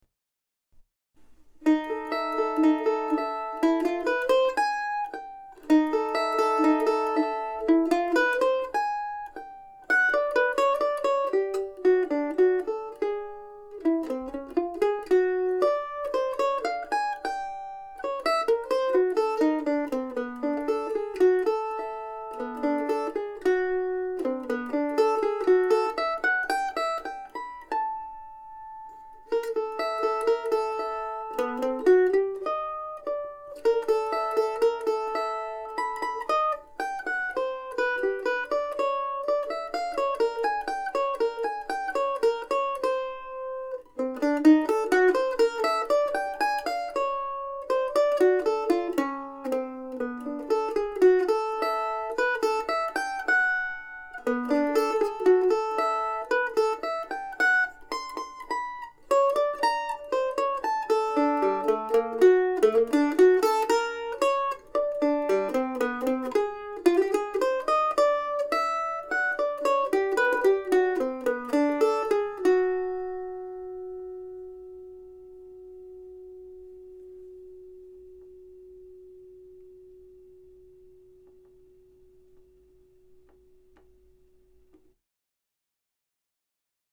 And yet another short piece for solo mandolin. This one recorded last weekend.